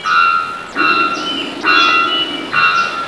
(All of these samples are 8-bit, sorry.)
Three-Wattled Bell-bird
The Bell-bird page presents the discovery that the Bell-bird's distinctive metallic "bonk" sound appears to consist of three simultaneous tones roughly a semitone apart, perhaps created by multiple independent vocal resonators.
A Bell-bird recording [.wav]
bell_birds.wav